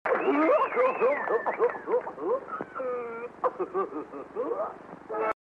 Goofy S Annoying Laugh